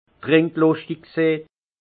Haut Rhin trinkluschtig sin Français qui aime boire
Ville Prononciation 68 Munster